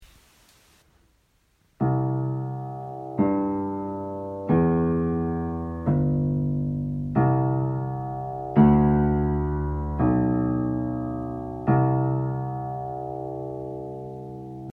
low_sounds.mp3